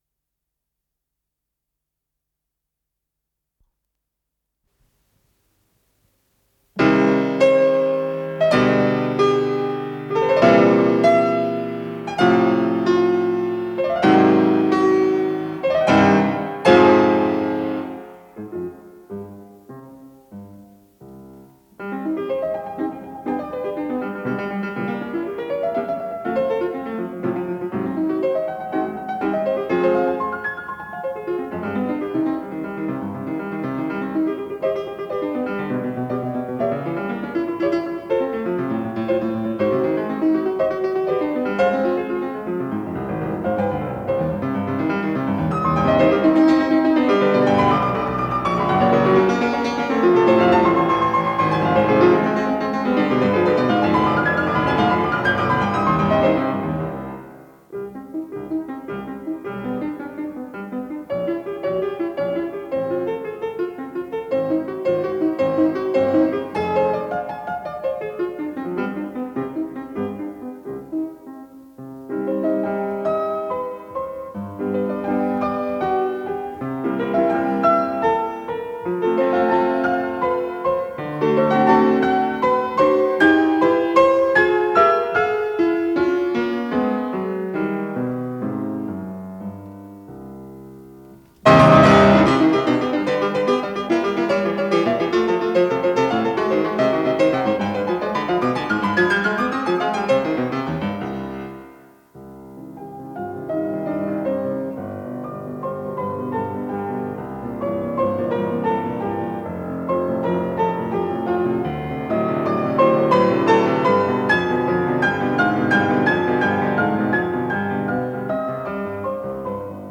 Исполнитель: Анатолий Ведерников - фортепиано
До минор